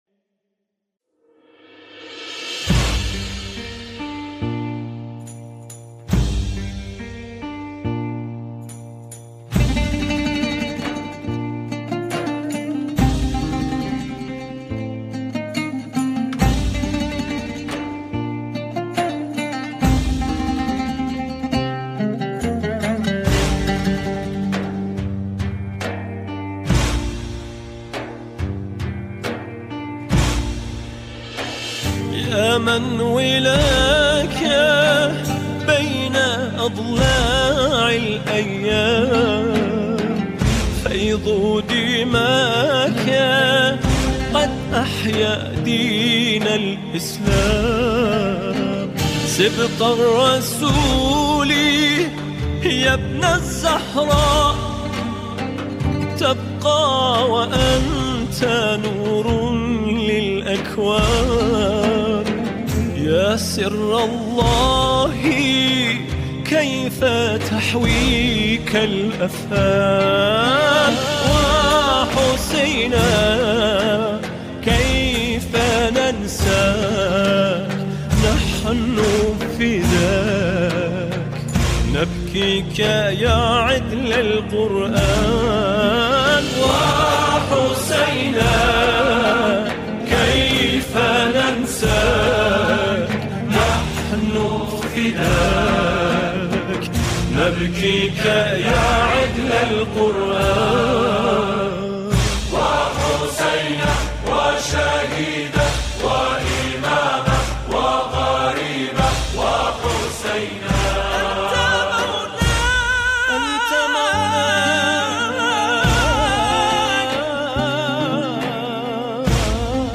سرودهای امام حسین علیه السلام
همخوانی شعری به مناسبت “ماه محرم”
گروهی از جمعخوانان